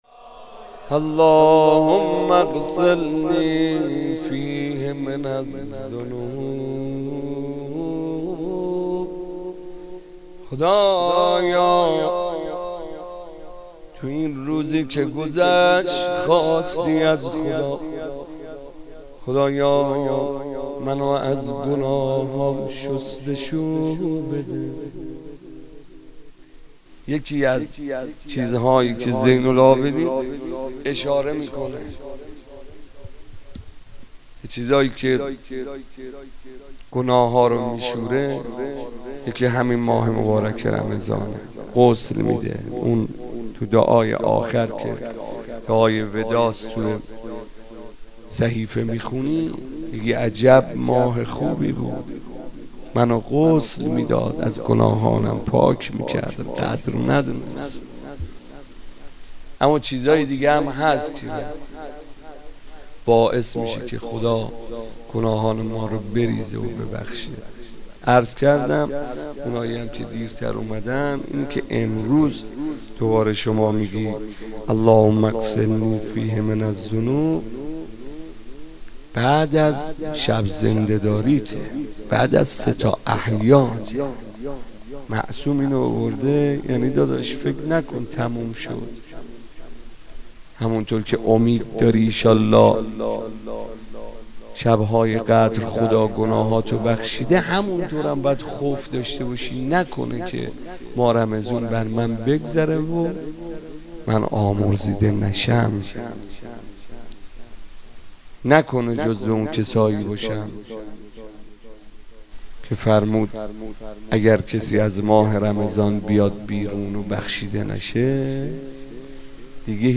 مناجات
روضه